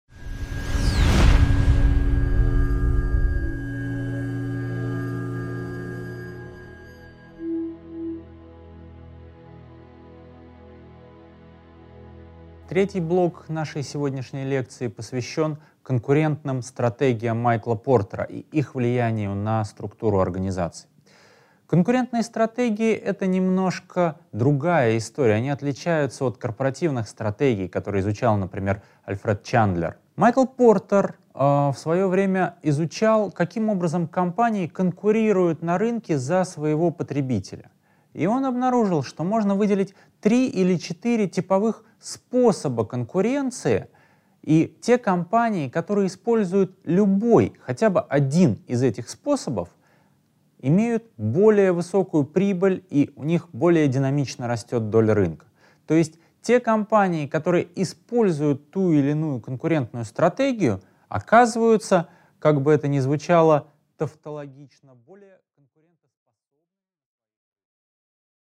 Аудиокнига 4.3. Модель Портера | Библиотека аудиокниг